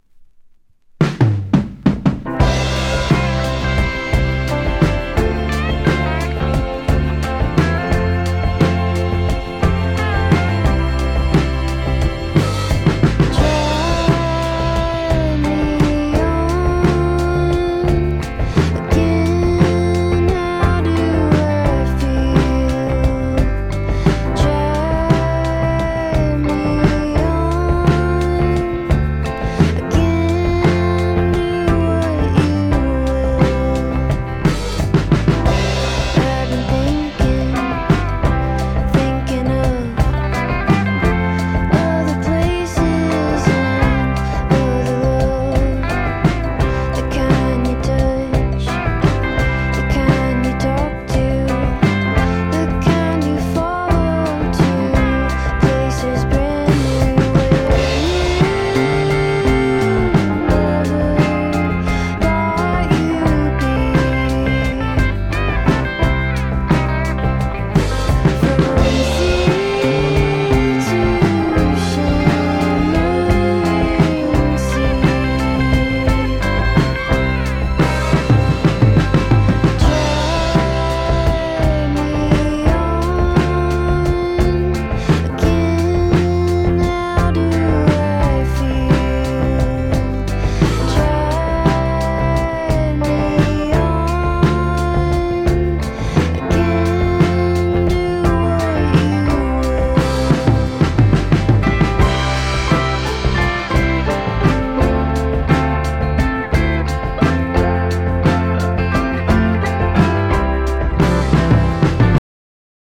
ナッシュビルのSSWによるデビューアルバム。カントリーロックからアメリカーナの楽曲が瑞々しく収録されてます。